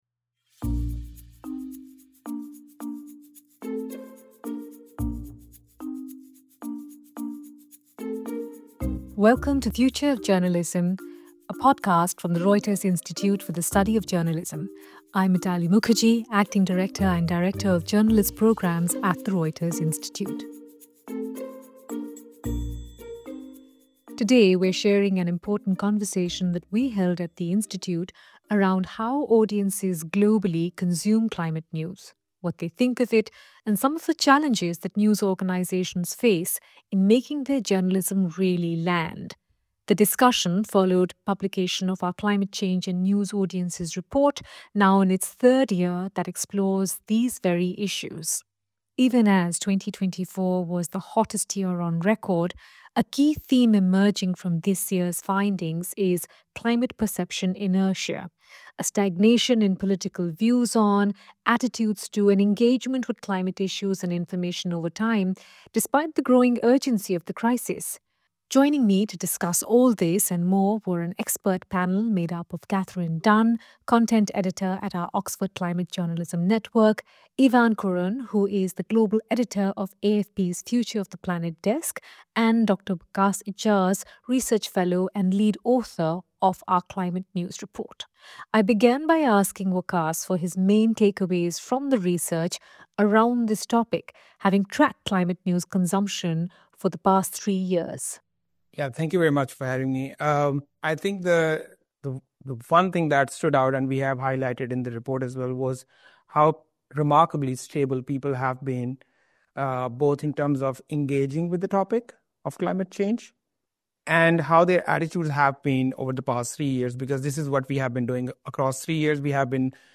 In this episode of Future of Journalism we are sharing an edited conversation that we held at the institute around how audiences globally consume climate news, what they think of it and some of the challenges that news organisations face in making their climate journalism really land.